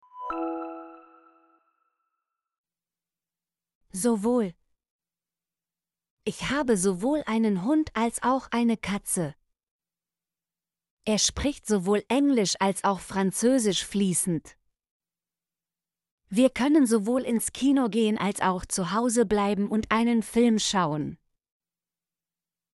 sowohl - Example Sentences & Pronunciation, German Frequency List